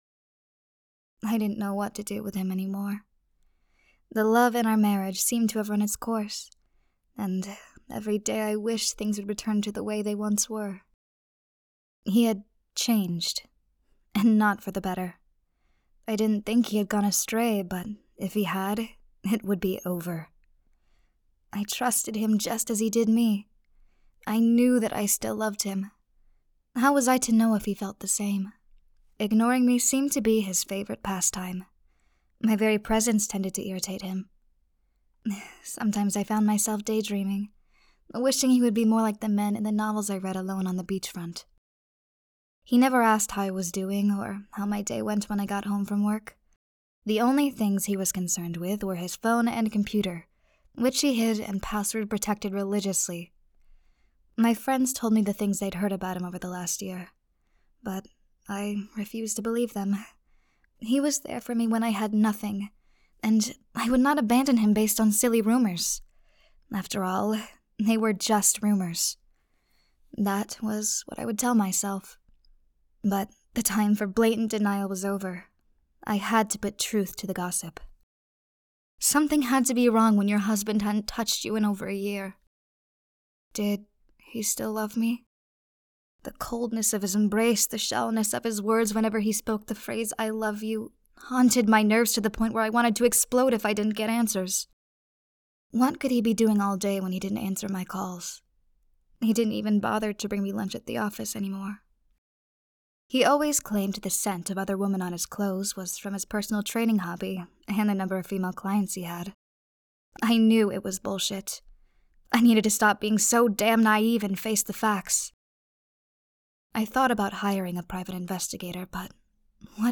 Lover’s Gift – Audiobook